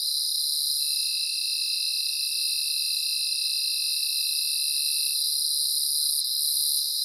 insectnight_12.ogg